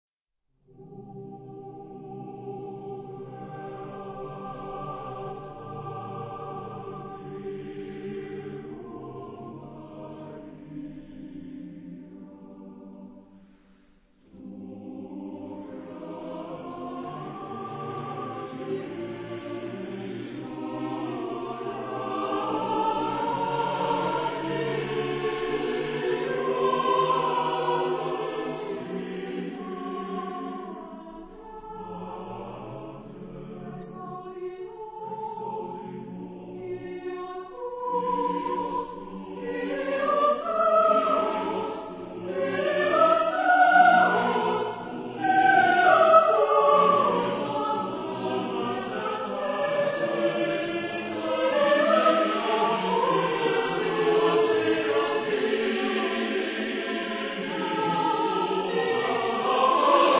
Epoque: 20th century  (1990-2000)
Genre-Style-Form: Sacred ; Choir
Mood of the piece: sustained
Type of Choir: SATB (div.)  (4 mixed voices )